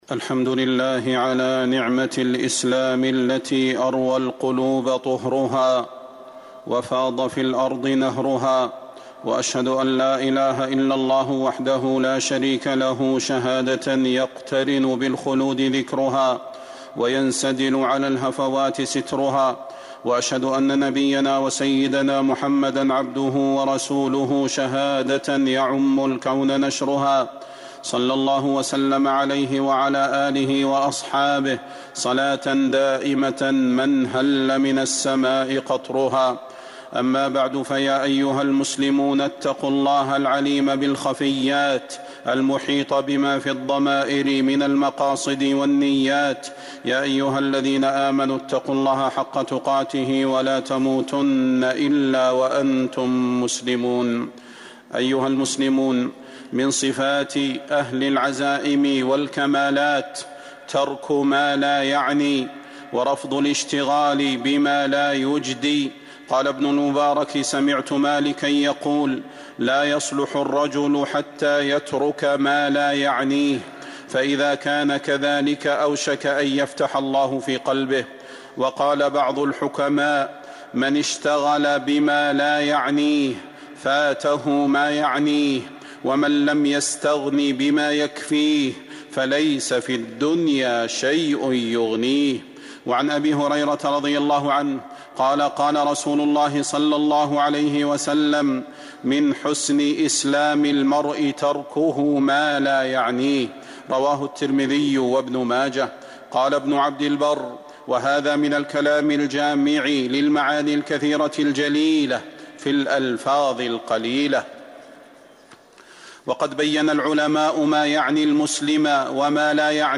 المدينة: أدب ما يعني المسلم ومالا يعنيه - صلاح بن محمد البدير (صوت - جودة عالية